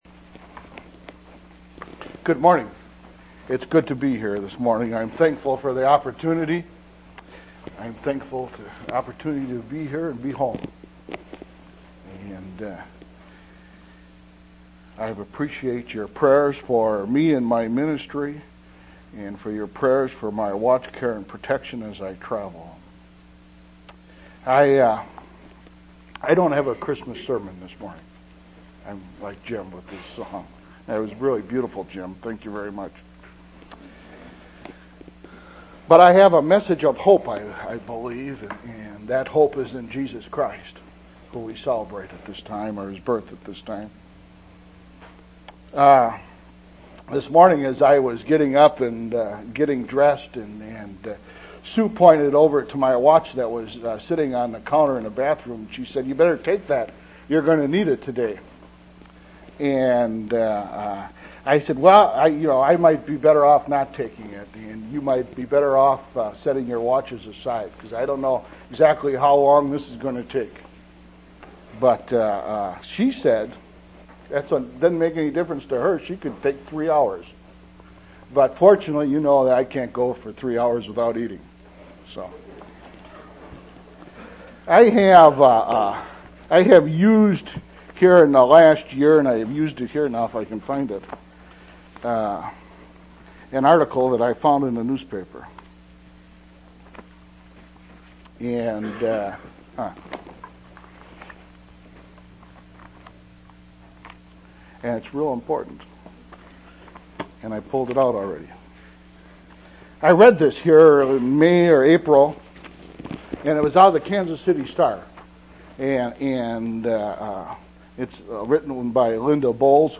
12/13/1998 Location: East Independence Local Event